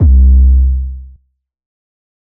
808_Oneshot_Kicker_C
808_Oneshot_Kicker_C.wav